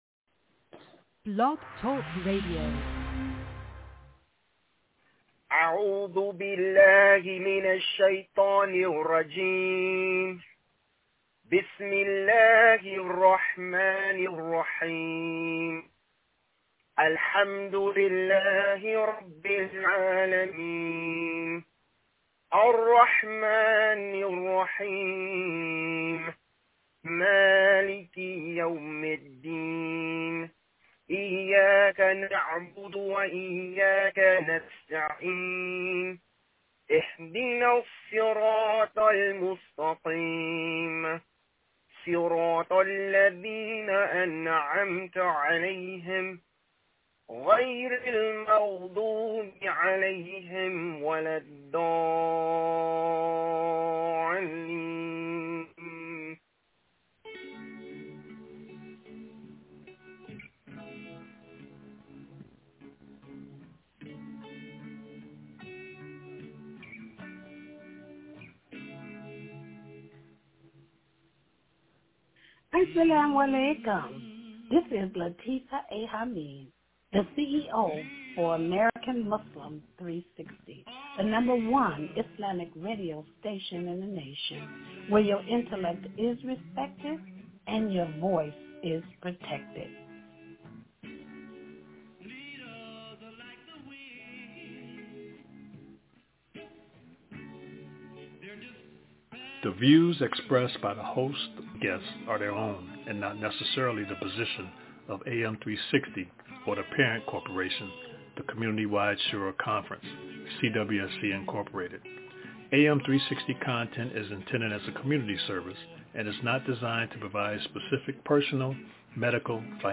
recitation-of-the-18th-sura-of-quran-titled-kahf-and-cwsc-friday-roundtable.mp3